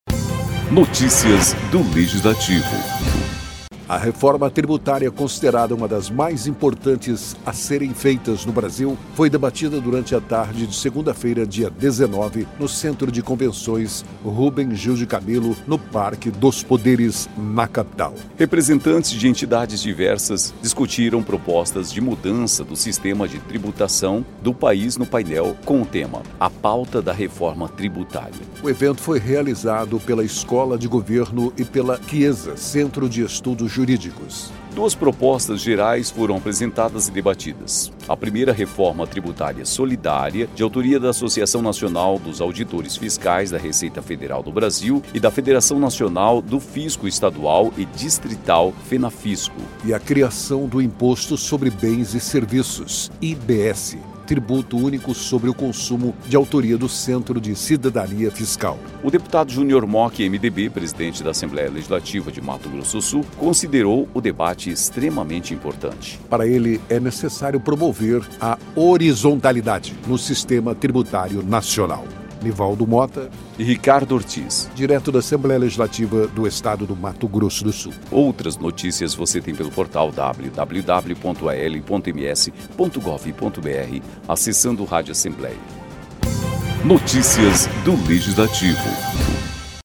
A reforma tributária, considerada uma das mais importantes a serem feitas no Brasil, está sendo debatida durante a tarde desta segunda-feira (19) no Centro de Convenções Rubens Gil de Camillo, no Parque dos Poderes, na Capital.